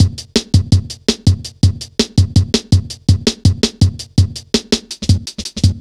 Index of /90_sSampleCDs/Zero-G - Total Drum Bass/Drumloops - 2/track 35 (165bpm)